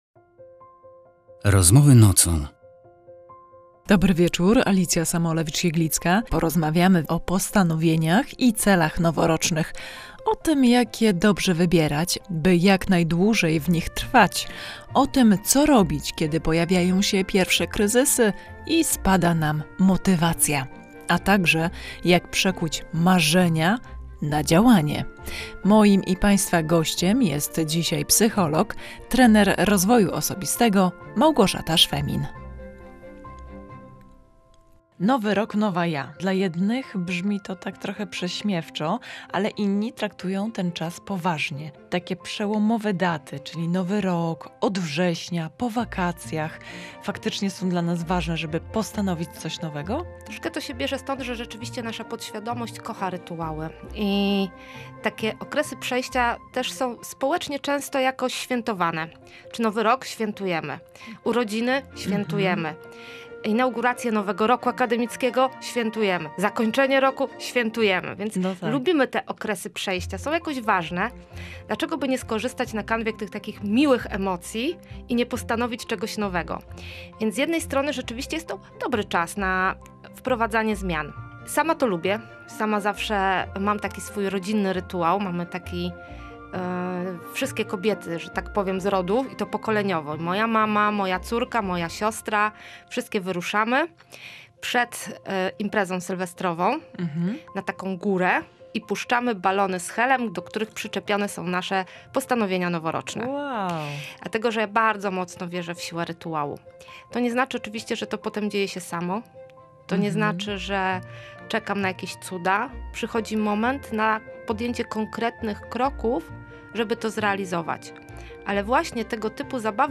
Posłuchaj rozmowy o przekuwaniu marzeń w działanie i trwaniu w postanowieniach noworocznych: